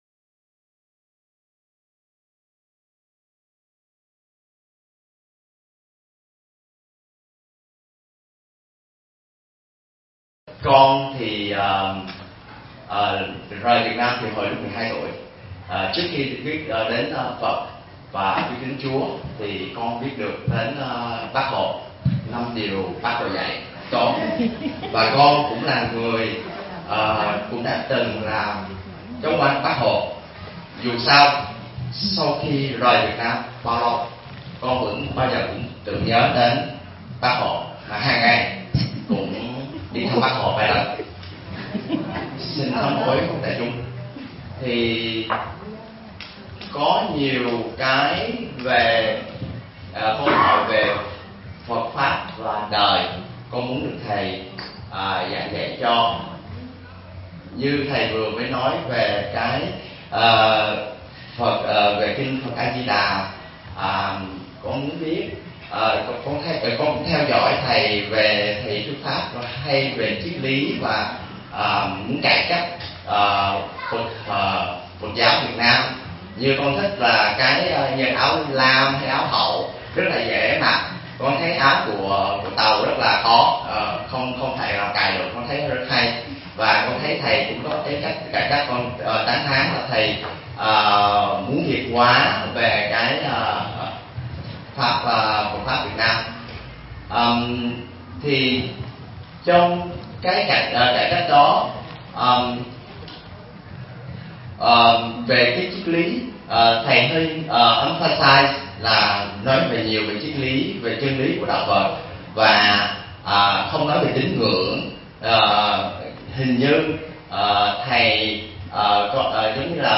Vấn đáp: Vãng sanh tây phương, hiểu đúng về Xá Lợi
giảng tại Như Lai thiền tự, Hoa Kỳ